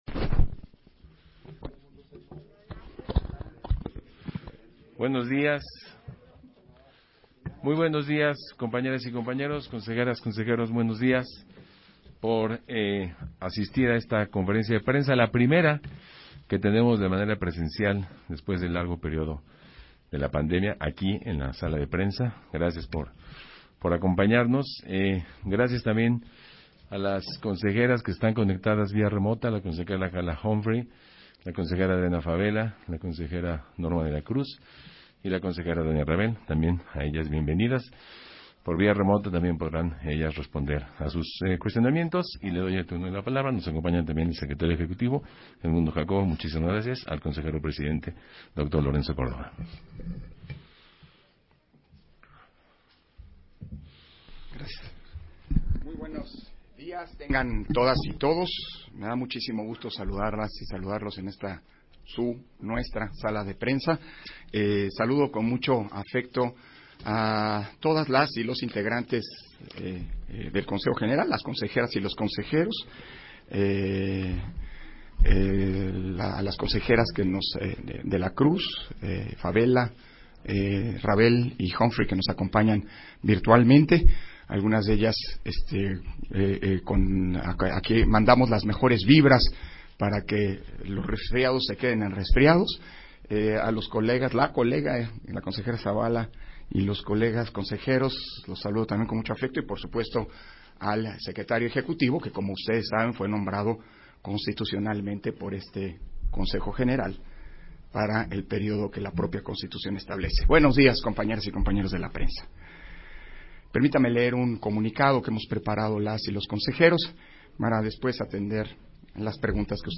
141222_AUDIO_CONFERENCIA-DE-PRENSA - Central Electoral